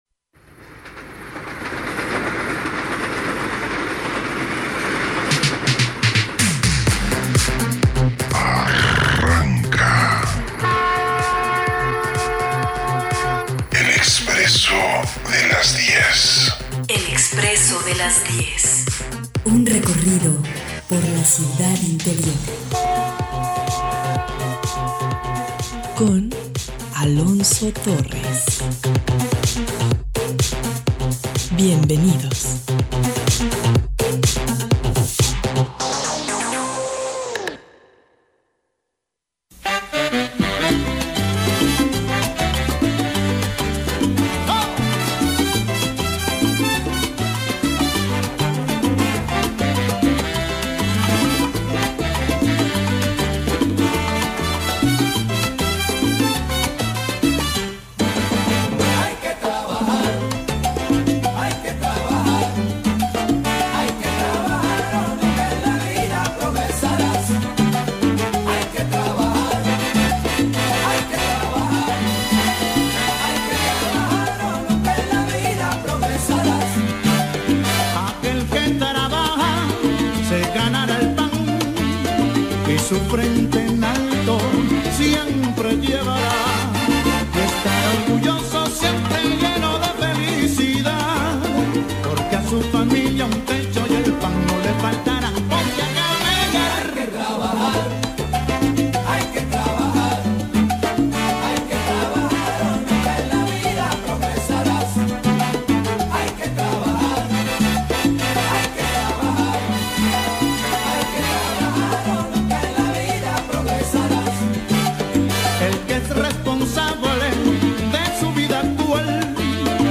Con el propósito de apoyar a las y los jóvenes en una de las decisiones más importantes de su vida, la Universidad de Guadalajara realiza la XXVII edición de Expo Profesiones, escucha en este podcast de El Expresso de las 10 las voces de los protagonistas en vivo desde el Pabellón Universitario.